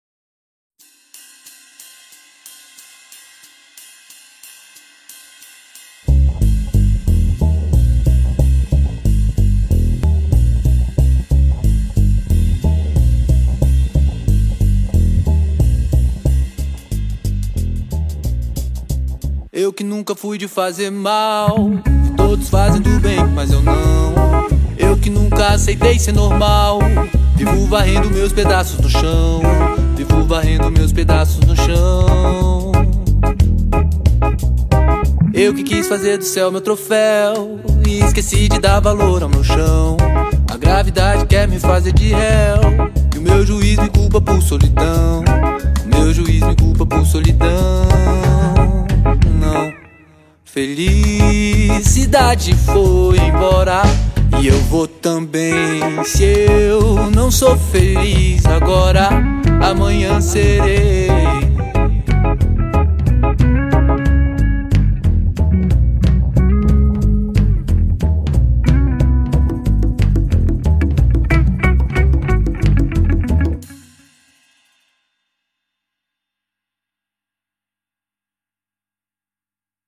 EstiloBlack Music